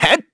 Evan-Vox_Attack1_kr.wav